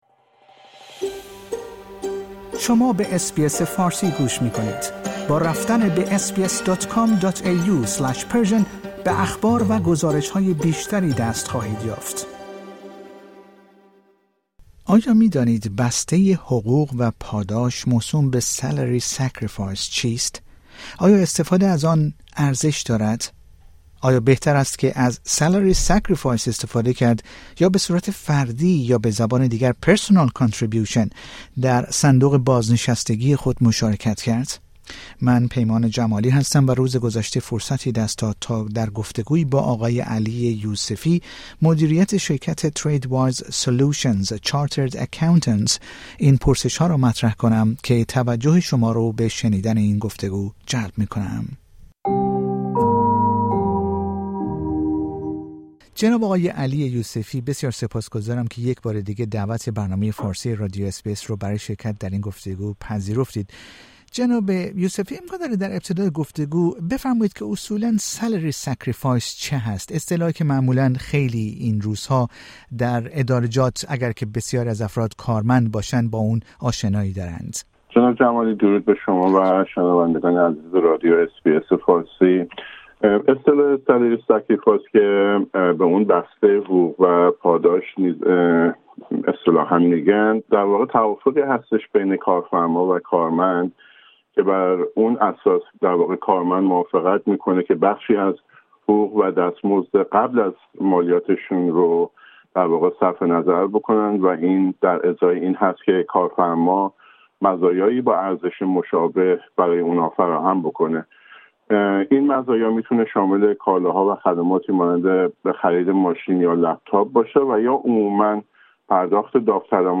در گفتگو با رادیو اس بی اس فارسی به آنها پاسخ می دهد